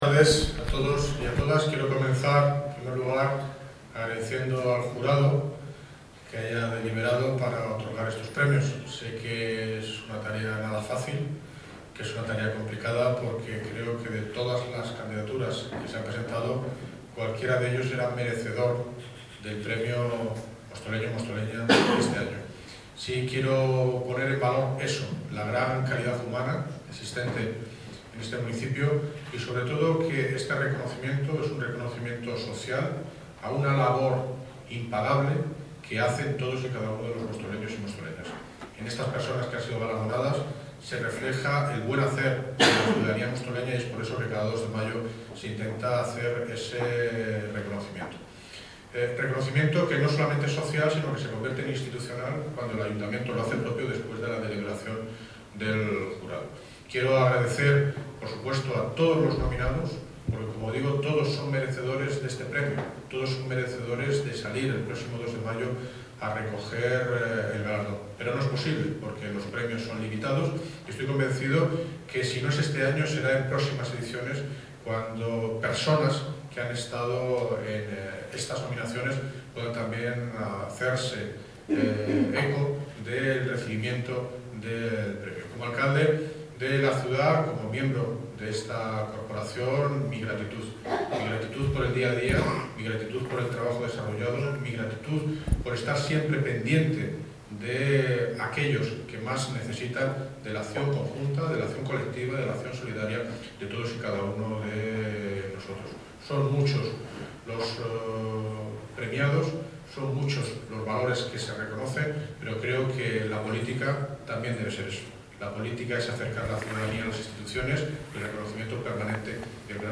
Audio - David Lucas (Alcald ede Móstoles) Sobre Premios Mostoleños